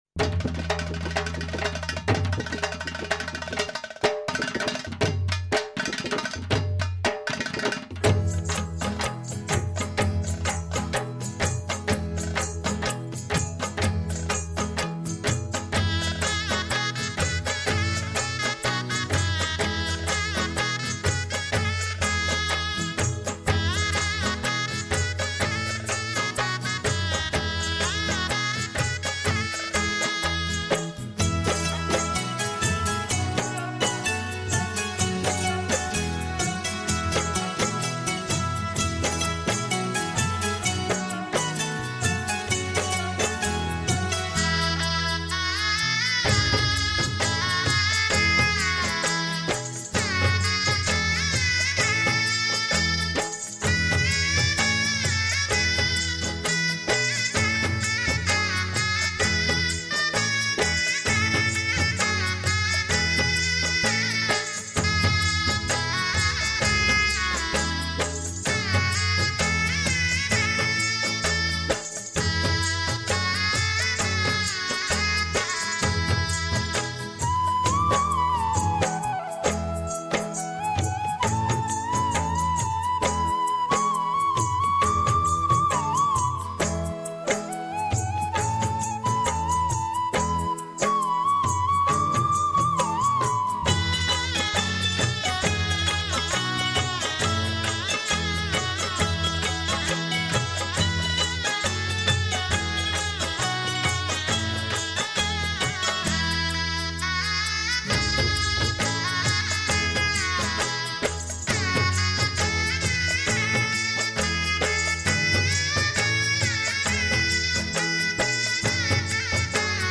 Rajasthani Vivah Geet Shehnai